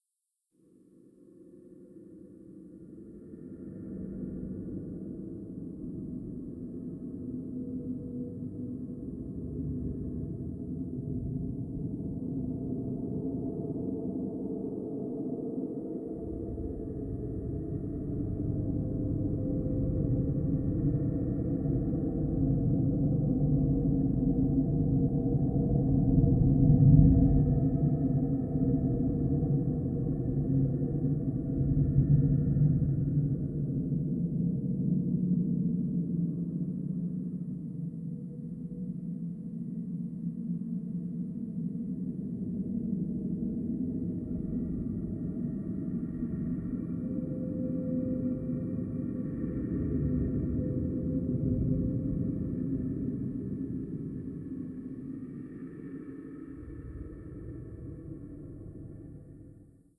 ambience2.wav